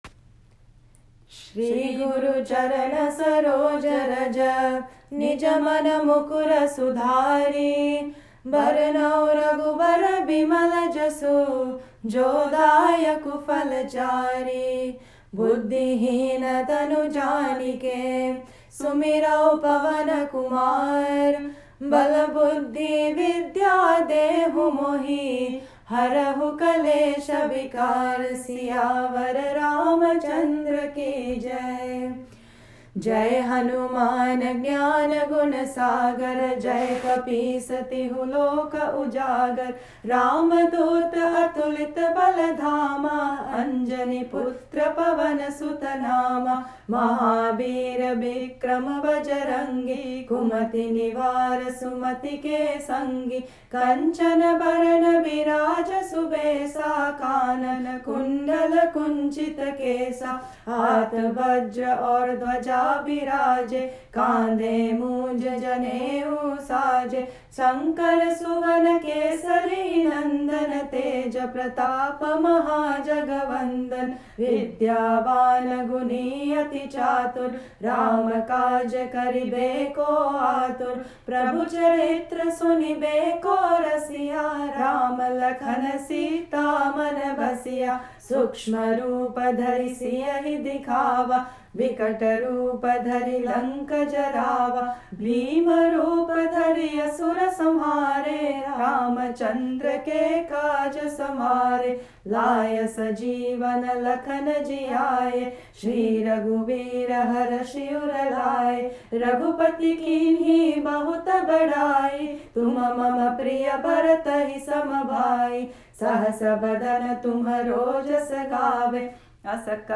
Hymn
There are many melodies based on folk tunes.
in the folk tune that they are familiar with